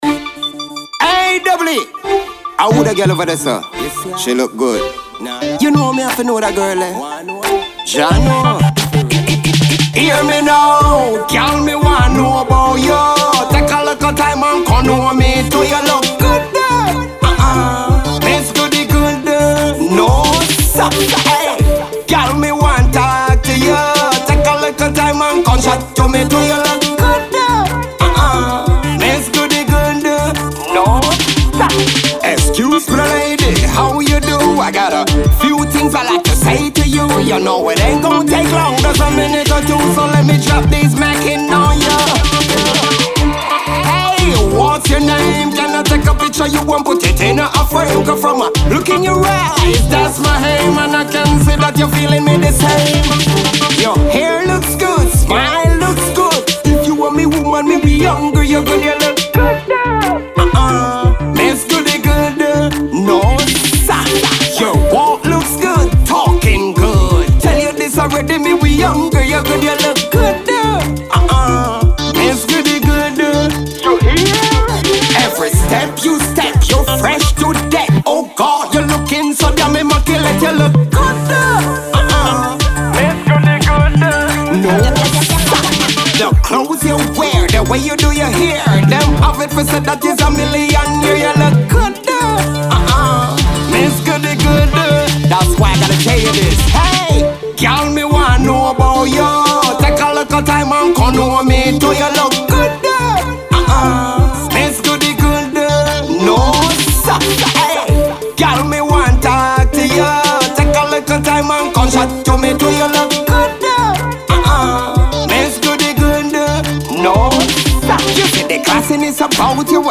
Dancehall reggae